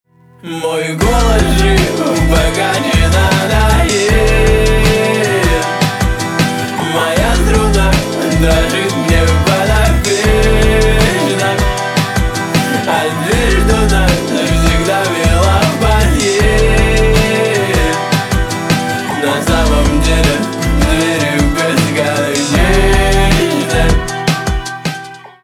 русский рок , гитара , барабаны
грустные